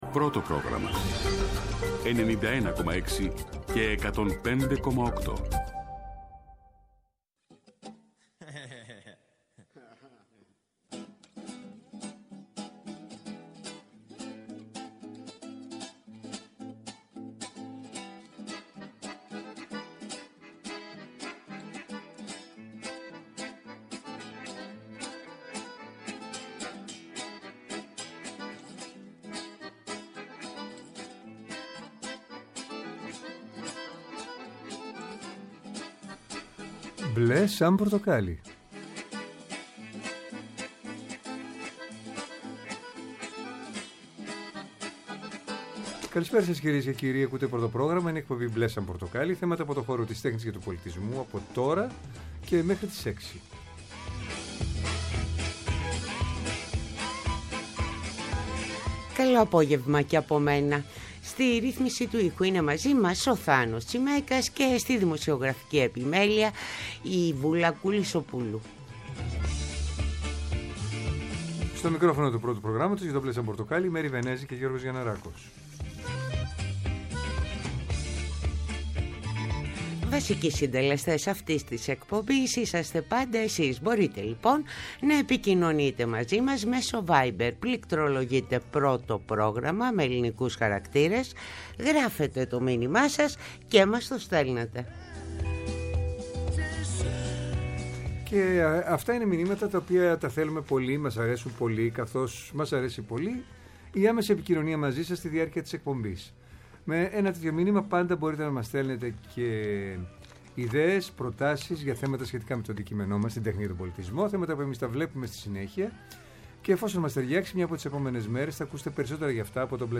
Μια εκπομπή με εκλεκτούς καλεσμένους, άποψη και επαφή με την επικαιρότητα.